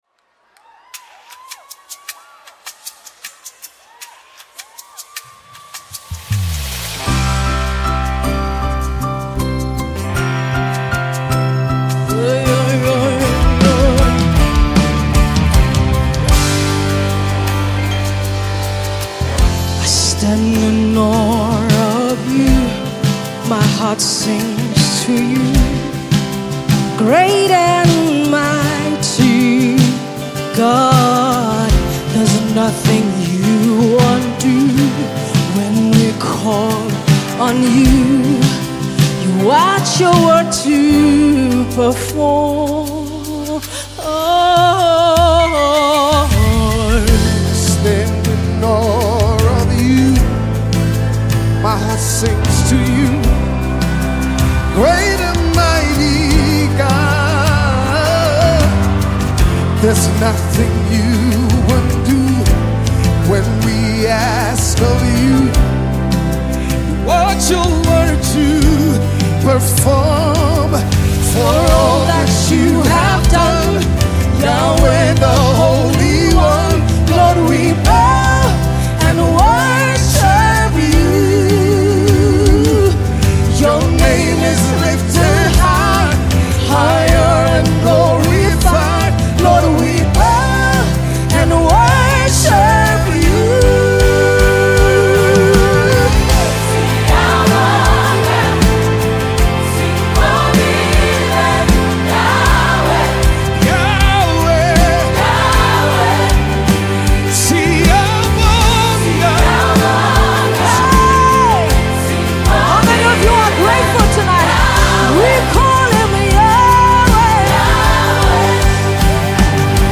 Home » Gospel
It delivers a sound that stays consistent throughout.